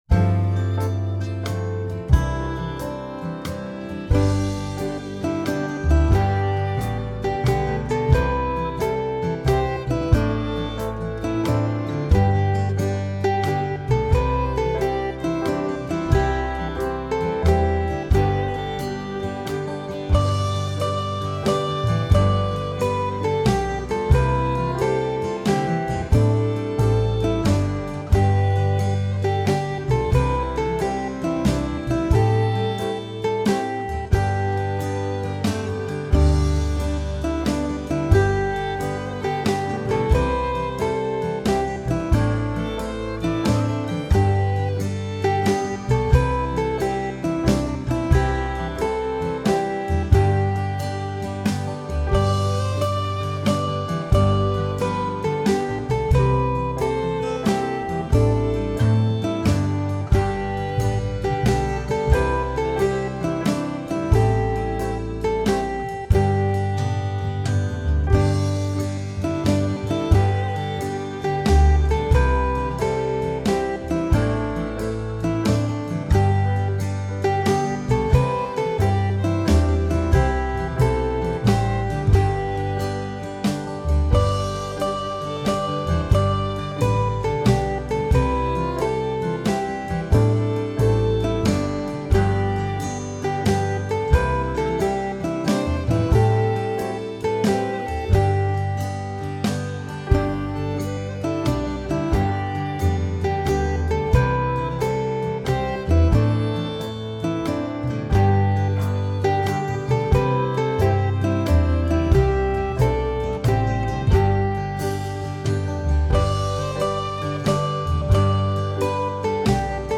It is a Korean melody and the text is from Genesis.
this backing is in the key